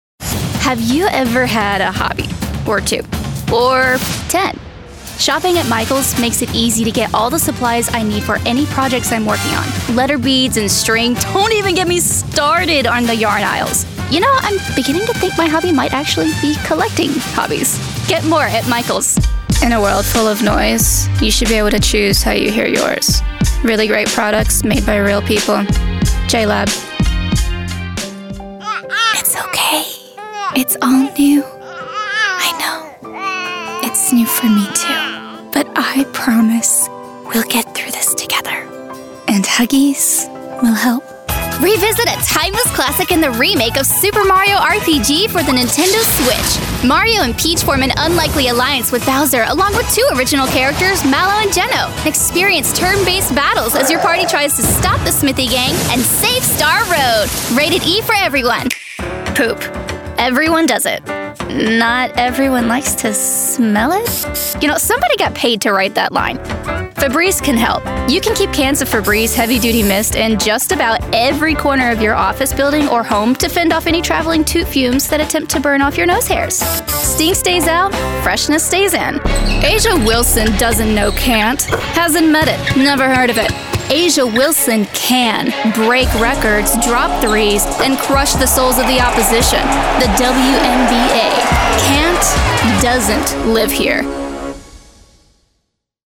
Spunky Sweetness with a Dark Side that Lights up the Room.
Commercial Demo
• Broadcast quality home studio
• XLR Condenser Mic, Focusrite Scarlett Solo 3rd Gen